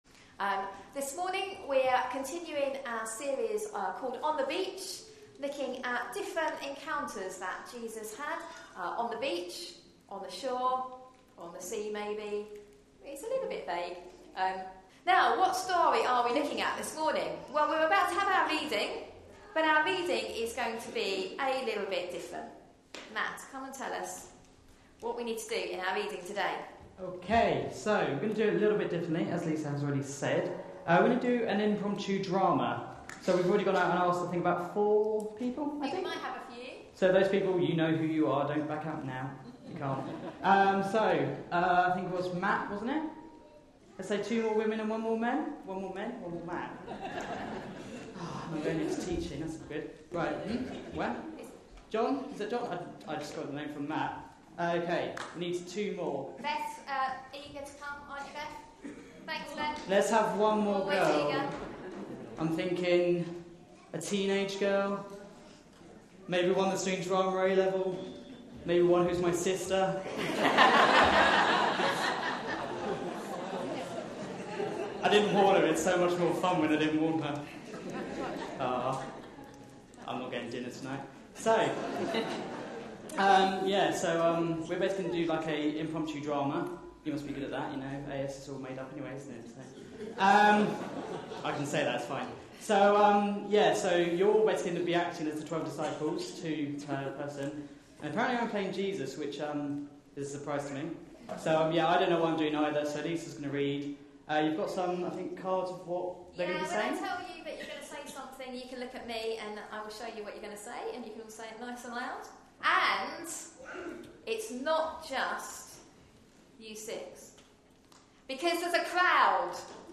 A sermon preached on 24th August, 2014, as part of our On The Beach. series.